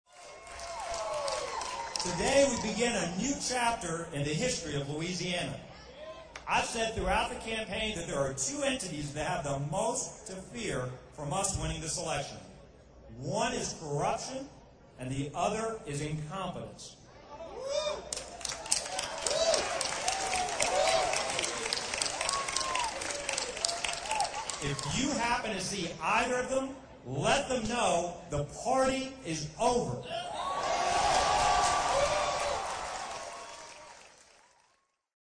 -- Bobby Jindal, Louisiana Governor-Elect victory Speech